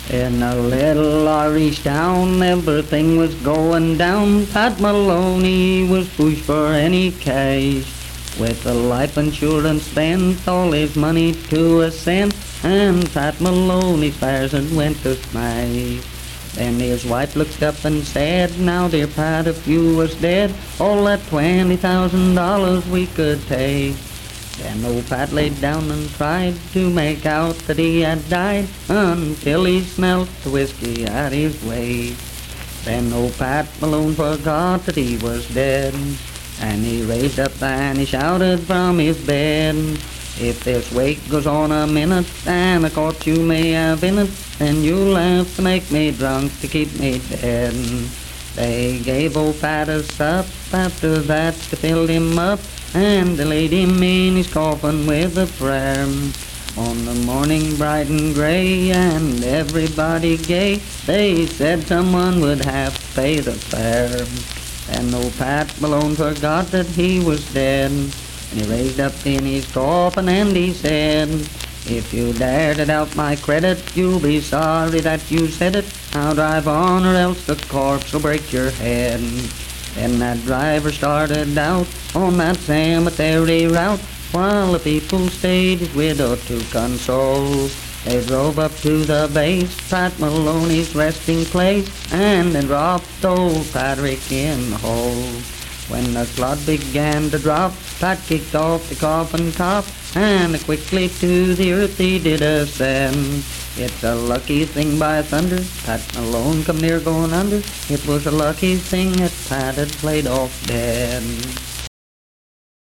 Unaccompanied vocal music performance
Voice (sung)
Wirt County (W. Va.)